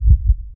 heart_beats
fast_1.wav